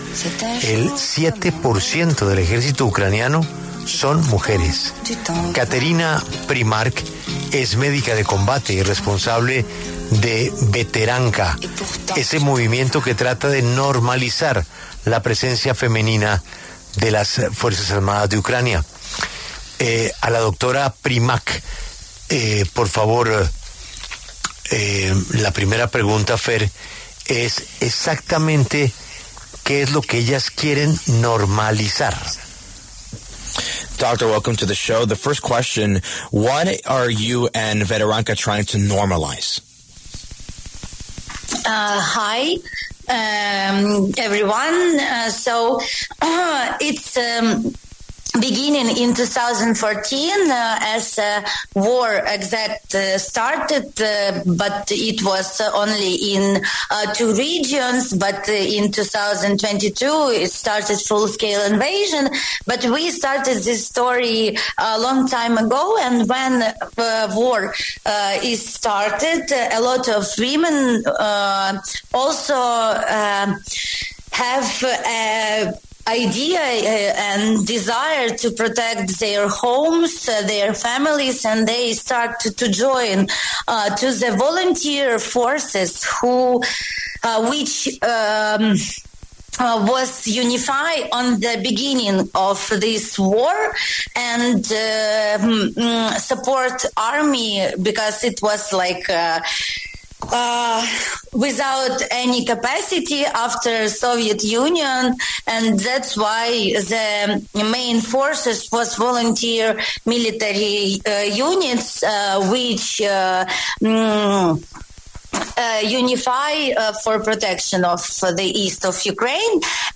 La W habló con una médica de combate que resaltó el rol de la mujer ucraniana en la guerra con Rusia.